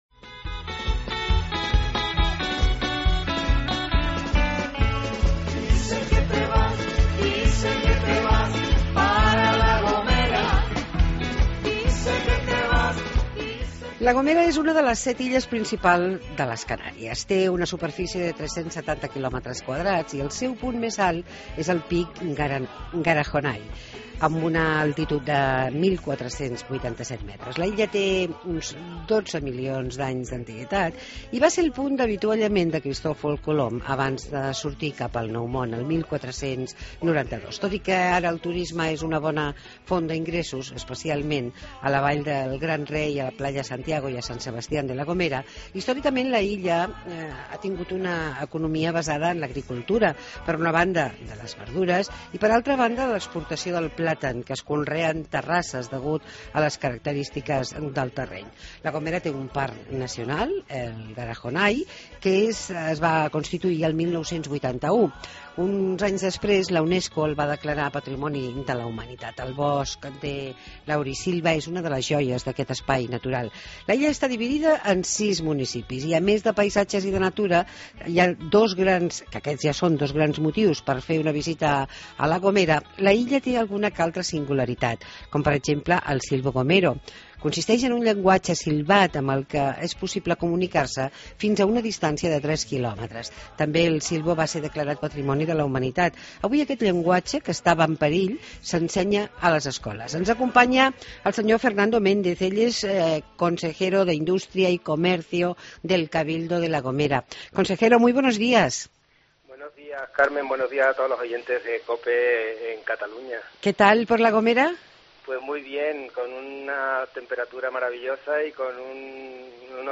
Entrevista a D. Fernando Méndez Borges, consejero de turismo de La Gomera